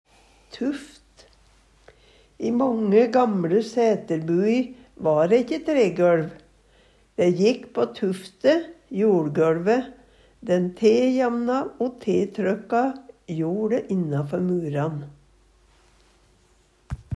tuft - Numedalsmål (en-US)